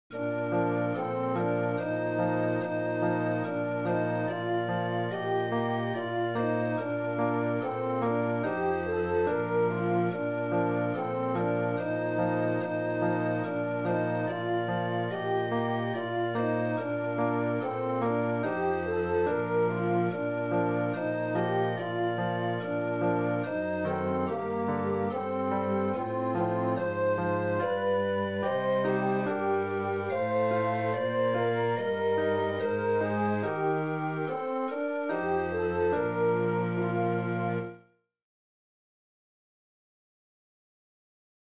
Chorproben MIDI-Files 508 midi files